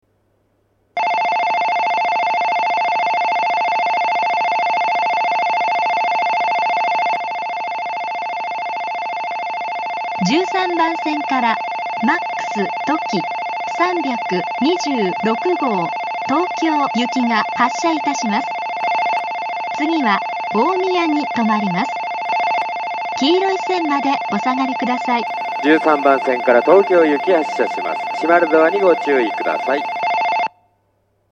在来線では全ホーム同じ発車メロディーが流れますが、新幹線ホームは全ホーム同じ音色のベルが流れます。
１３番線発車ベル 主に上越新幹線が使用するホームです。
Ｍａｘとき３２６号東京行の放送です。